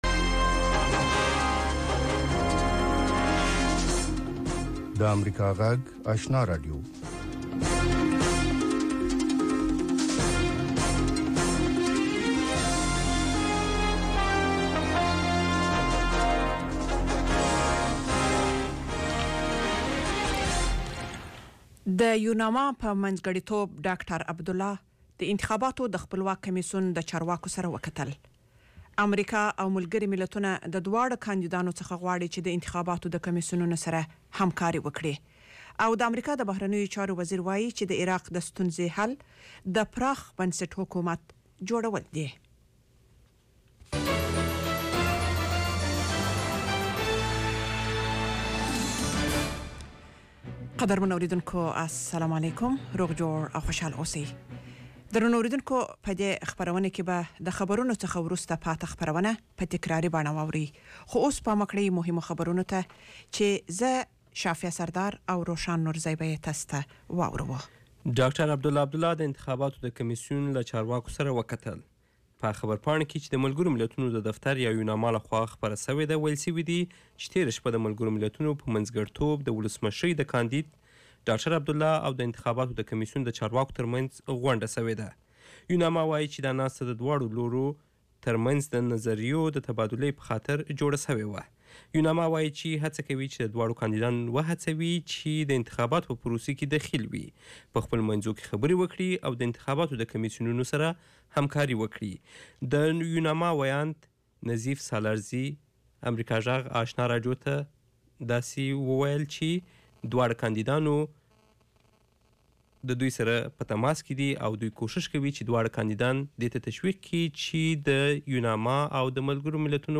یو ساعته پروگرام: تازه خبرونه، او د نن شپې تېر شوي پروگرامونه په ثبت شوي بڼه، هنري، علمي او ادبي مسایلو په اړه د شعر، ادب او بیلا بیلو هنرونو له وتلو څیرو سره.